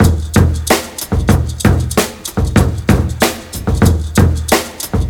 • 94 Bpm Drum Groove E Key.wav
Free drum beat - kick tuned to the E note. Loudest frequency: 1508Hz
94-bpm-drum-groove-e-key-W2B.wav